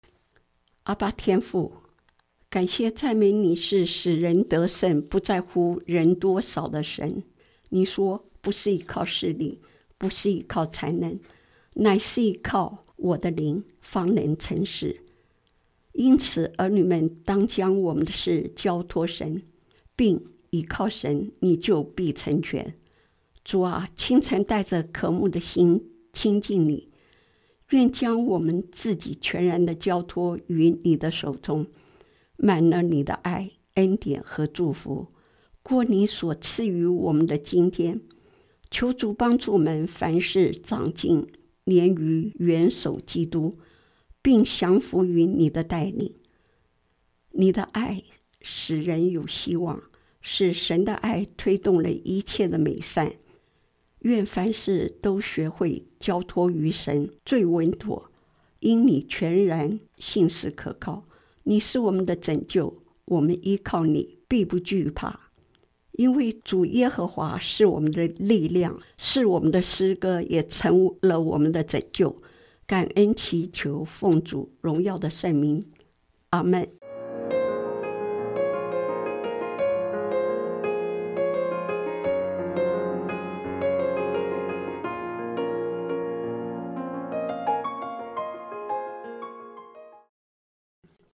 今日祈祷– 神的爱推动一切的美善